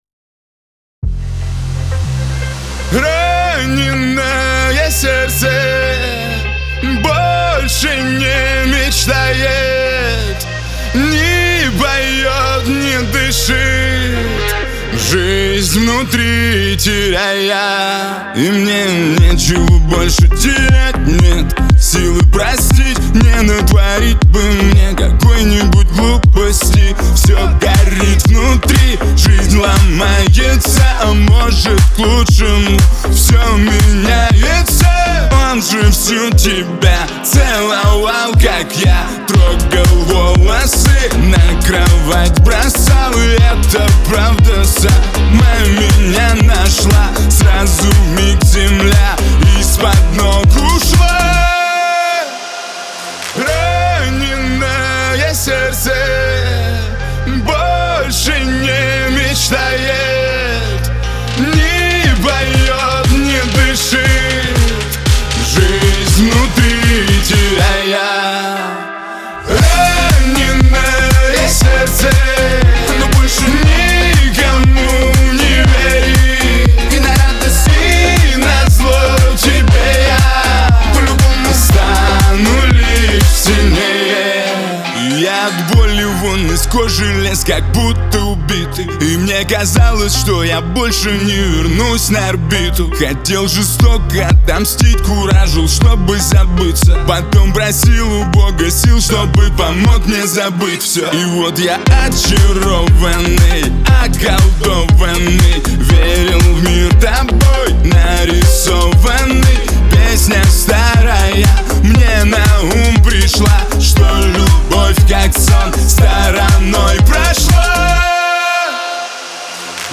эстрада
грусть
ХАУС-РЭП